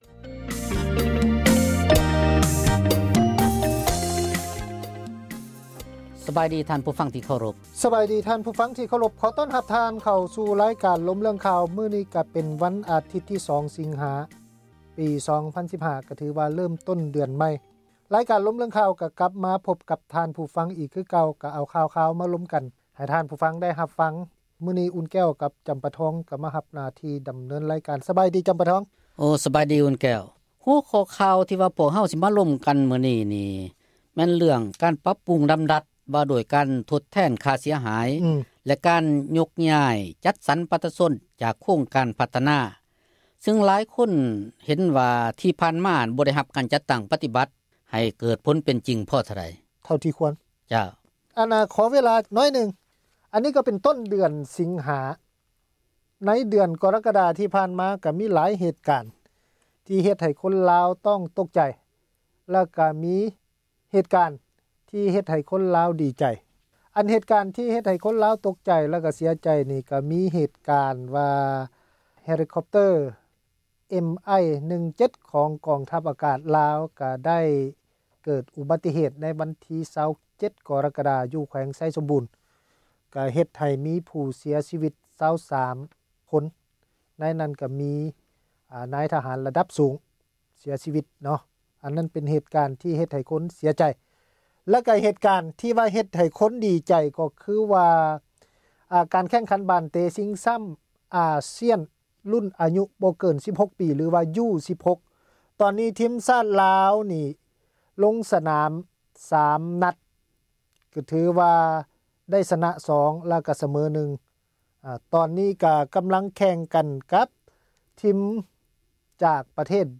ການສົນທະນາ ເຖິງເຫດການ ທີ່ເກີດຂຶ້ນ ໃນແຕ່ລະມື້ ທີ່ມີຜົນກະທົບ ຕໍ່ຊີວິດປະຈໍາວັນ ຂອງຊາວລາວ ທົ່ວປະເທດ ທີ່ ປະຊາສັງຄົມ ເຫັນວ່າ ຂາດຄວາມເປັນທັມ.